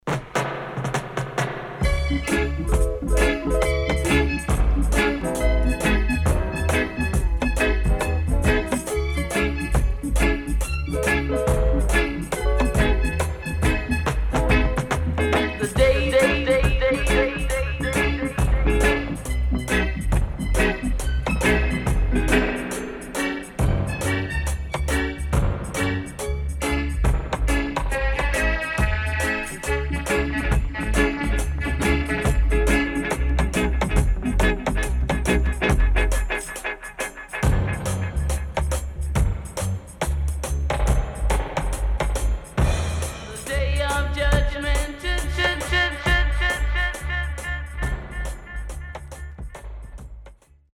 HOME > REISSUE [REGGAE / ROOTS]
Beautiful Roots Vocal & Dubwise